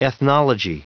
Prononciation du mot ethnology en anglais (fichier audio)
Prononciation du mot : ethnology